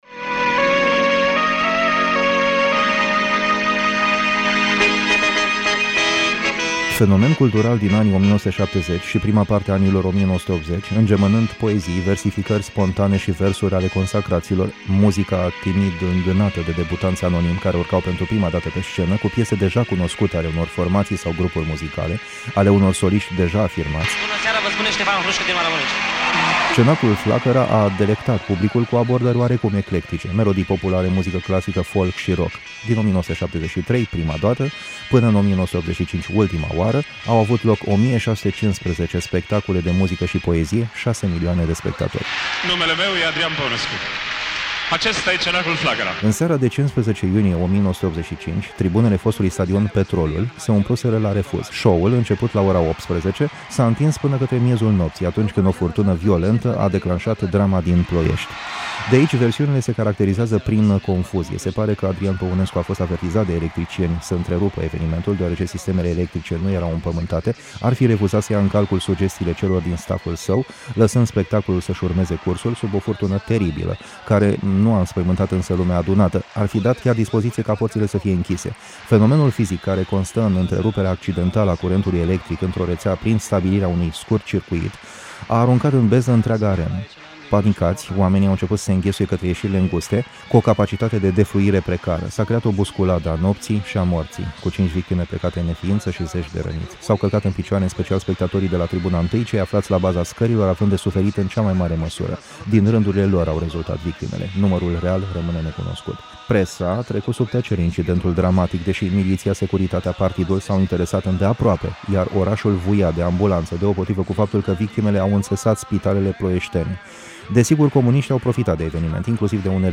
Feature